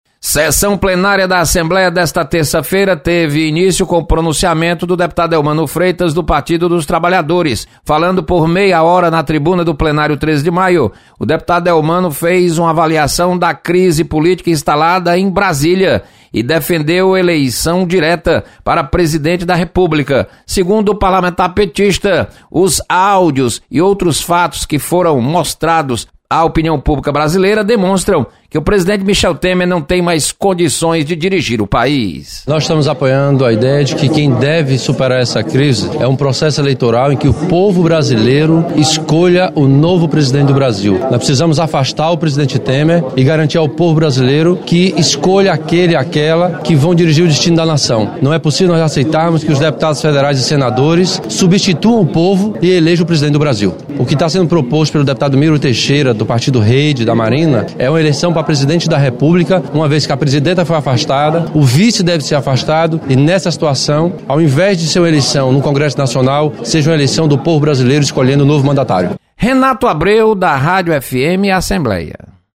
Deputado Elmano de Freitas defende eleições diretas para acabar com crise política brasileira.